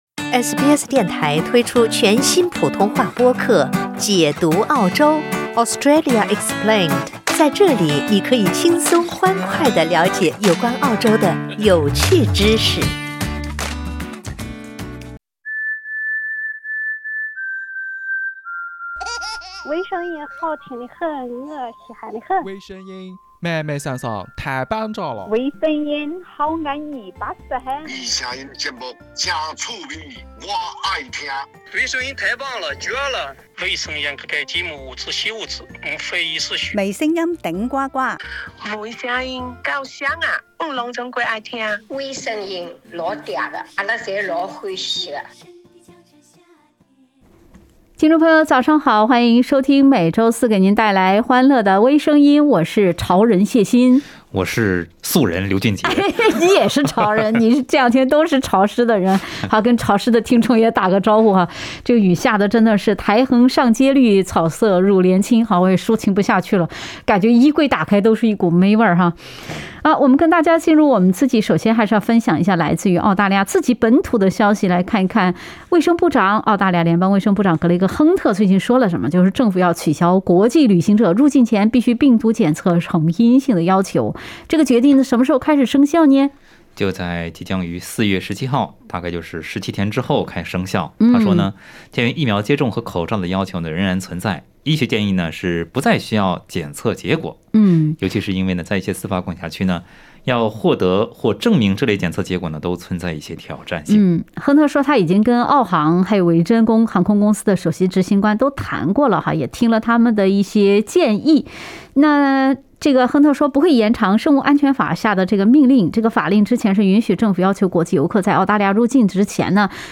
（點擊封面圖片，收聽輕松對話）